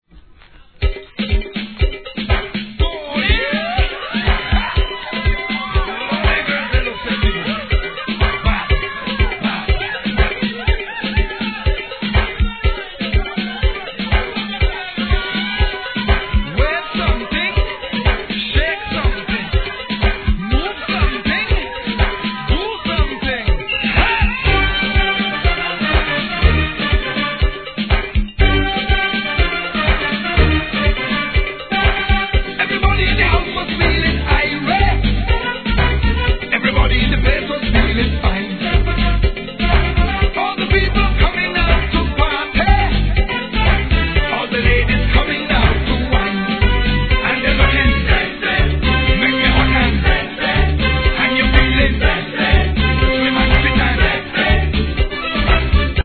REGGAE
レア・ソカ・ナンバー!